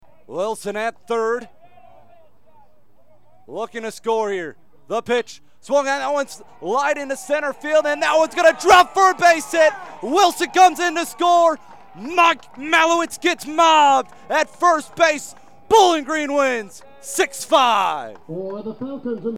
RADIO CALL: